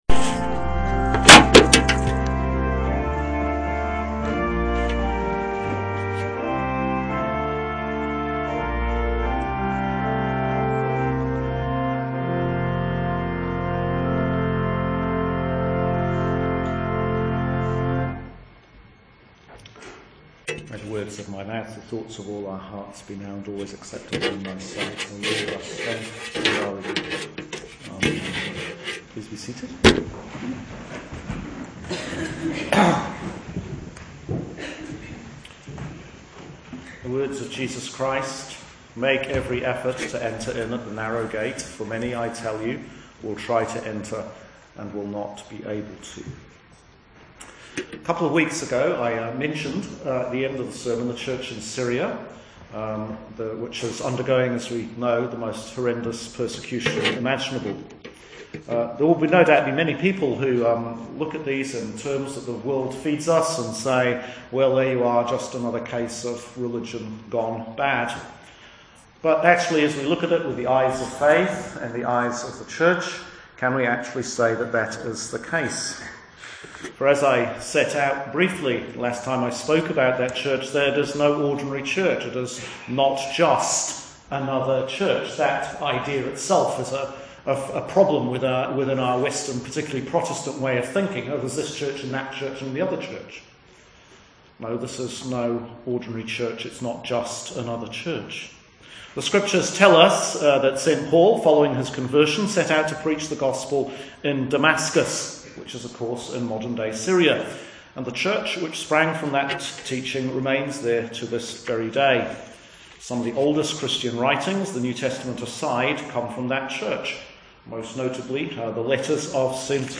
Sermon on Stewardship